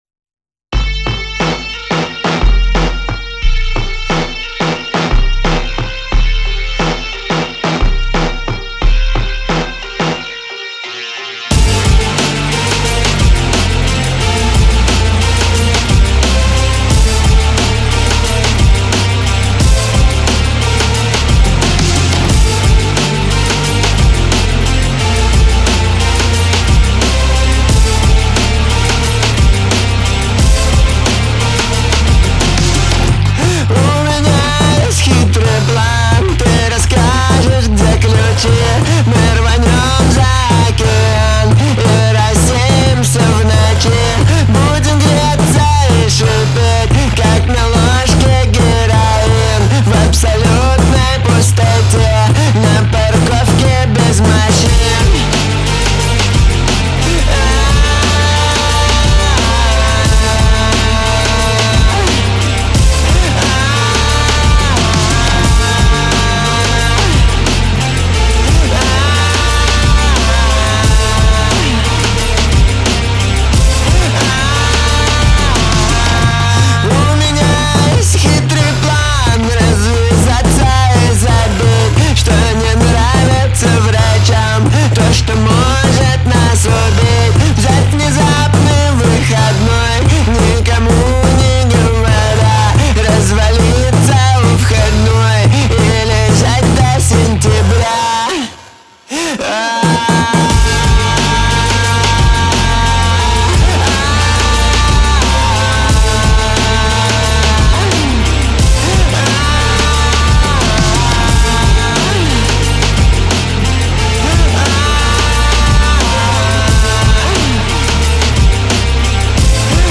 Трек размещён в разделе Русские песни / Рок / 2022.